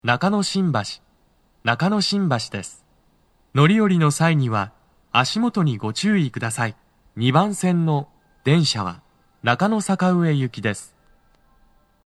スピーカー種類 TOA天井型()
足元注意喚起放送が付帯されています。
2番線 中野坂上・池袋方面 到着放送 【男声